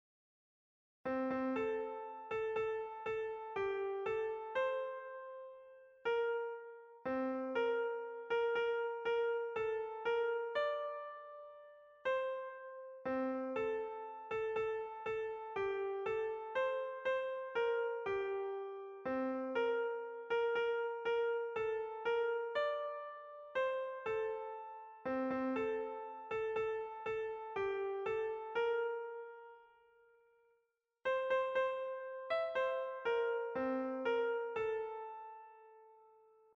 Heidschi bumbeidschi (Volkslied, frühes 19. Jh.)